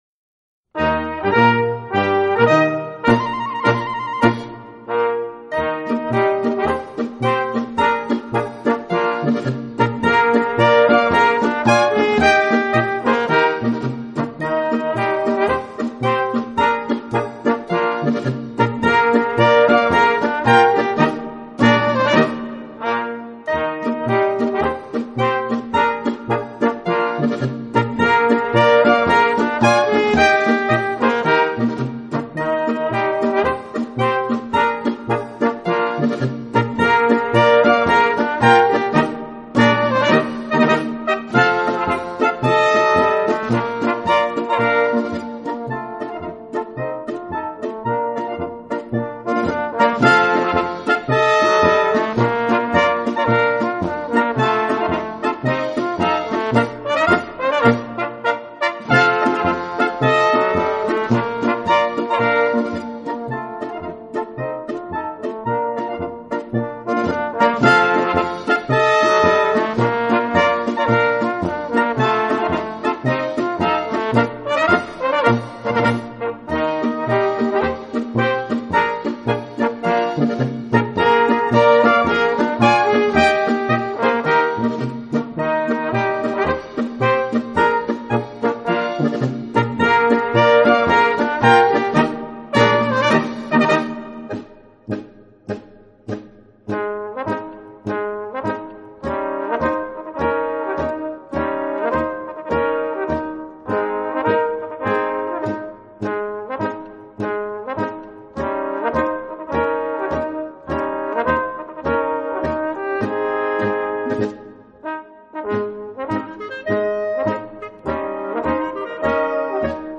für kleine Besetzung
Kleine Blasmusik-Besetzung